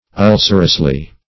[1913 Webster] -- Ul"cer*ous*ly, adv. --